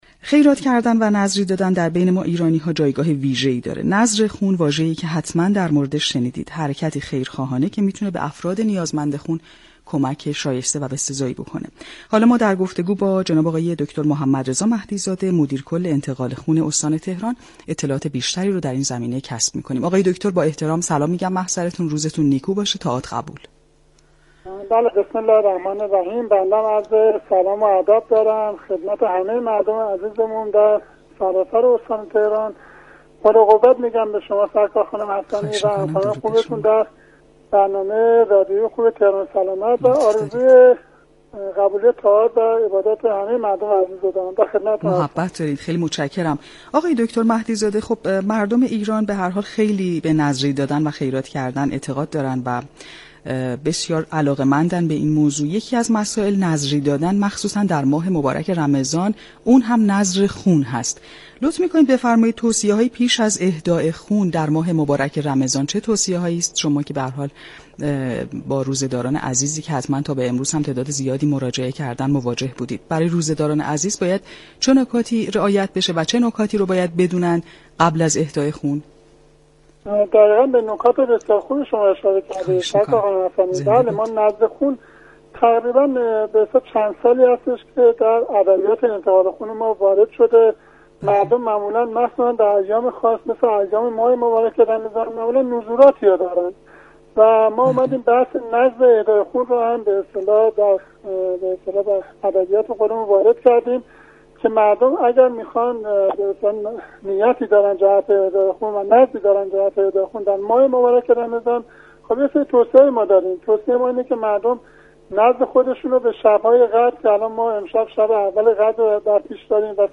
به گزارش پایگاه اطلاع رسانی رادیو تهران، نذر خون عملی شایسته است كه در بین ایرانیان جایگاهی ویژه دارد و می تواند كمك شایانی برای افراد نیازمند به خون باشد در همین راستا دكتر محمدرضا مهدی‌زاده مدیركل سازمان انتقال خون استان تهران در گفتگو با برنامه "تهران ما سلامت" 31 فروردین با اشاره به اینكه نذر خون چندسالی است در ادبیات سازمان انتقال خون وارد شده گفت: مردم در روزهای خاصی از سال مثل ماه مبارك رمضان، جهت ادای نذر خود، خون می‌دهند.